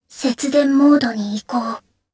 Kara-Vox_Dead_jp.wav